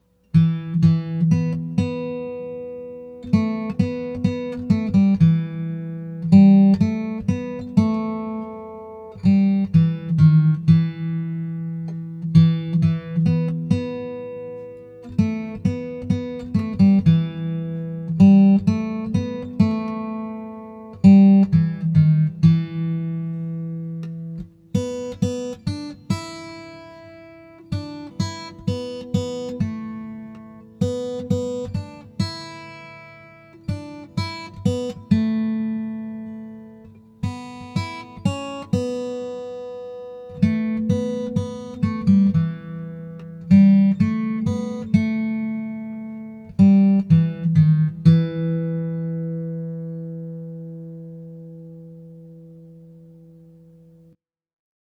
Mr. Garst’s article also contained a reproduction of the first publication of “A Poor Wayfaring Stranger” with its melody as we now know and love it best: built (mostly) from the notes of a pentatonic minor scale.
This is what the melody of Mr. Dale’s E minor setting sounds like. (It’s my guess that the flat next to the eighth note D in the first measure of the last staff is a typo, but I played it anyway.)